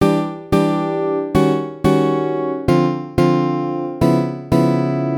マヌーシュジャズのターンアラウンドの練習
Em7-Eb7#9-Dm7-Db7#9
ターンアラウンド を使ったイントロ